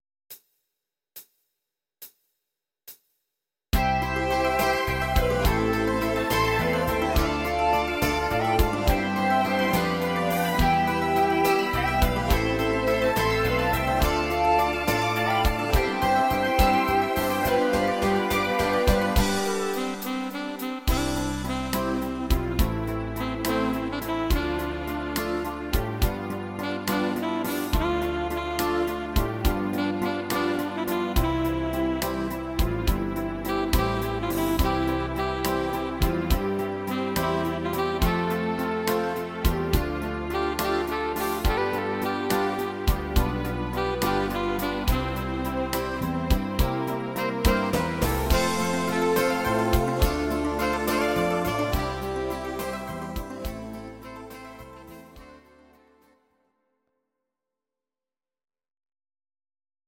These are MP3 versions of our MIDI file catalogue.
Please note: no vocals and no karaoke included.
Your-Mix: Oldies (2910)